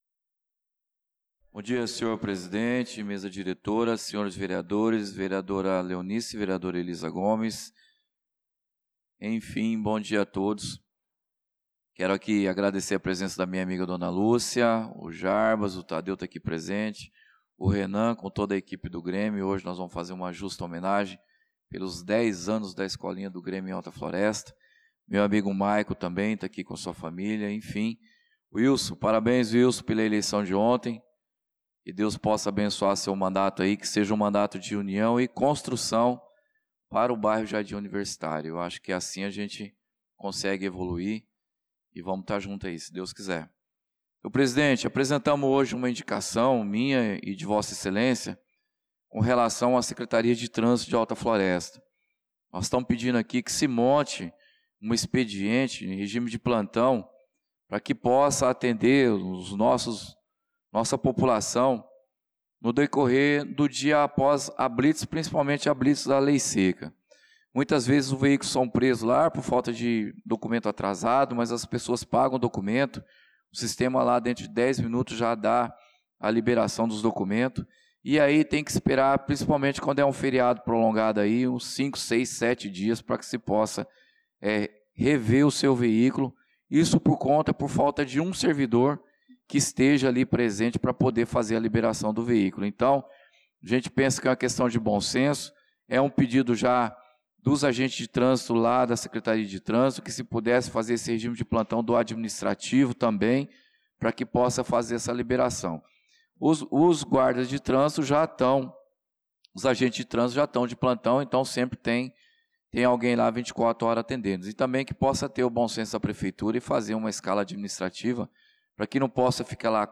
Pronunciamento do vereador Claudinei de Jesus na Sessão Ordinária do dia 28/04/2025